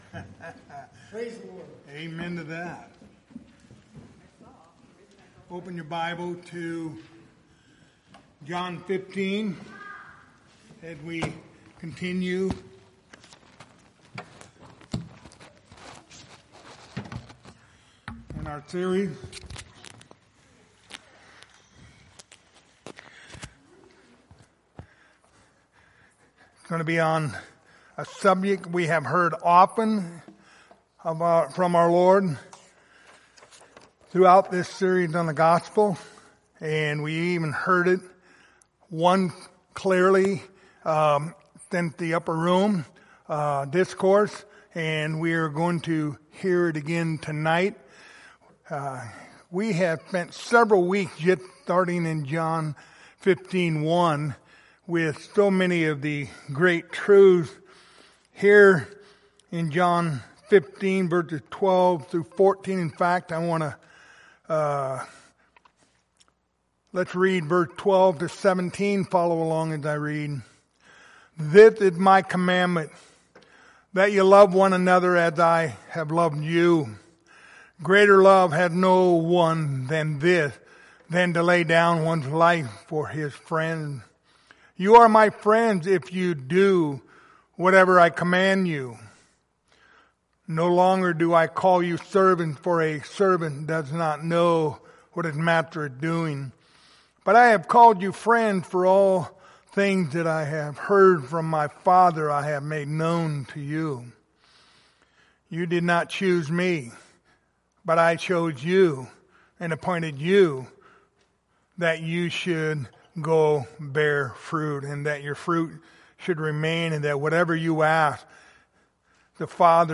Passage: John 15:12-17 Service Type: Wednesday Evening